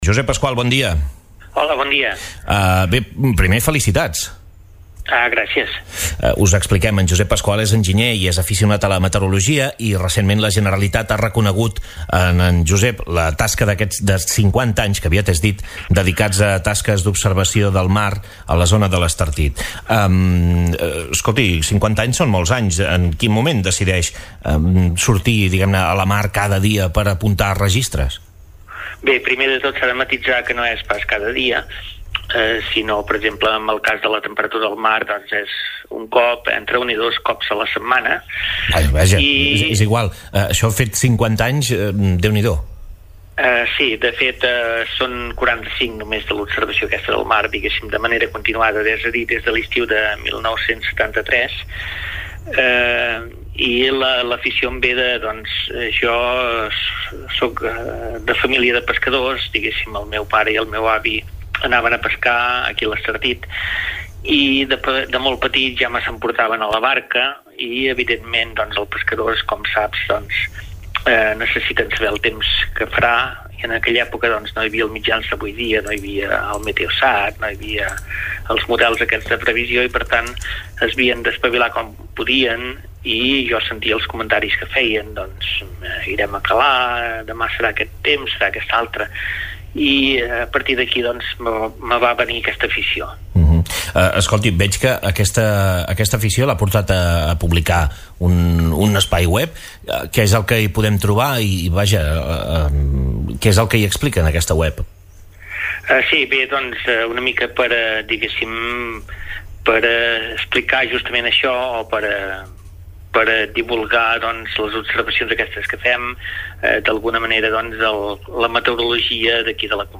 En aquest sentit, també ha indicat que un dels perills seria la desaparició d’alguna de les platges de la nostra costa. Podeu recuperar l’entrevista sencera aquí: https